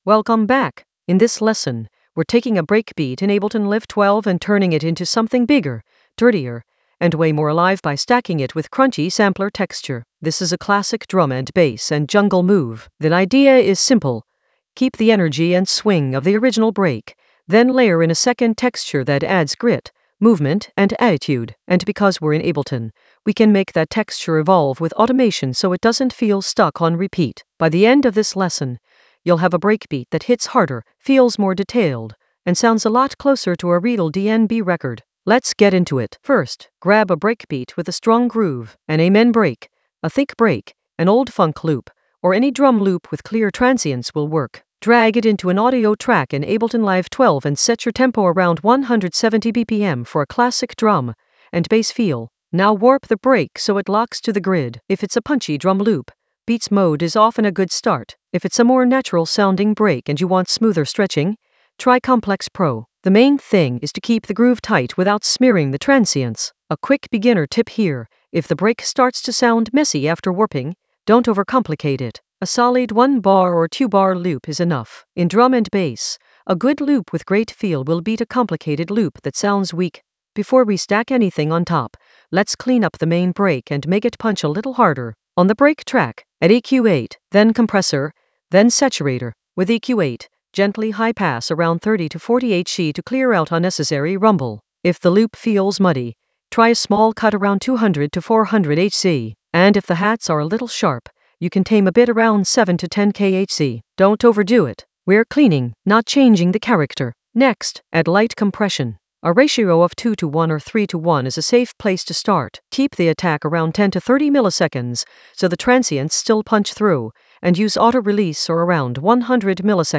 An AI-generated beginner Ableton lesson focused on Breakbeat in Ableton Live 12: stack it with crunchy sampler texture in the Automation area of drum and bass production.
Narrated lesson audio
The voice track includes the tutorial plus extra teacher commentary.